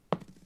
dice2.mp3